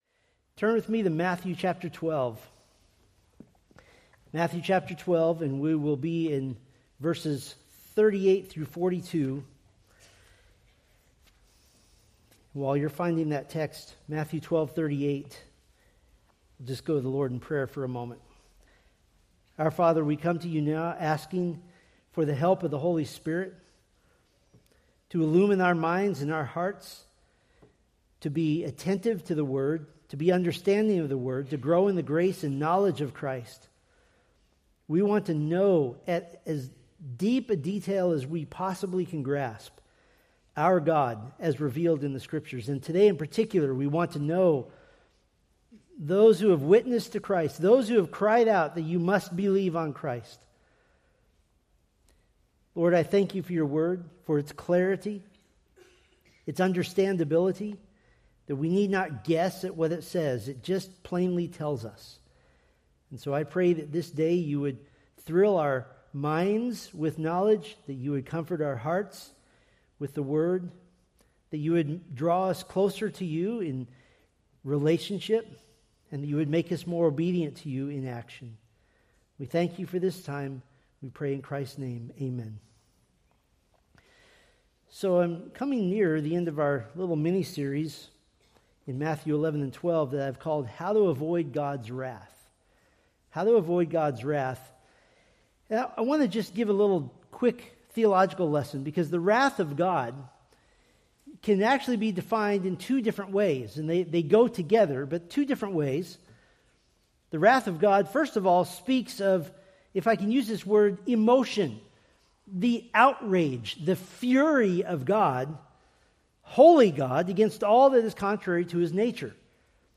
Preached January 18, 2026 from Matthew 12:38-42